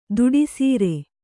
♪ duḍI sīre